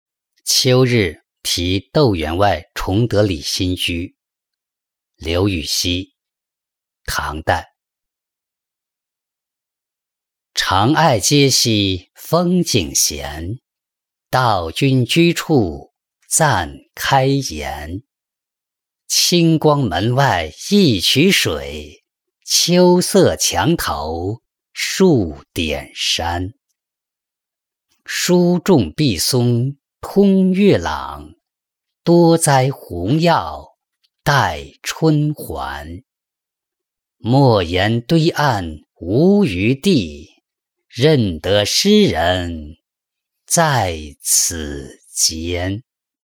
秋日题窦员外崇德里新居-音频朗读